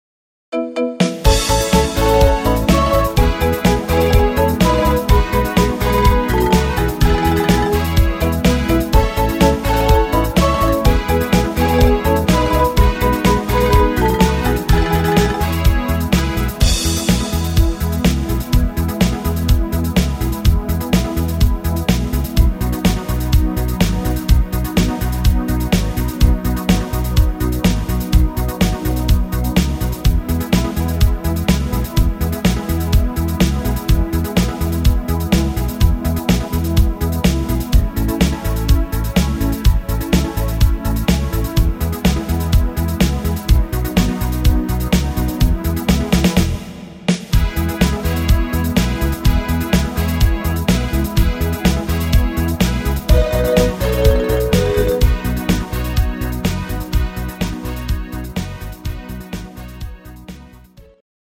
Rhythmus  Discofox
Art  Deutsch, Schlager 80er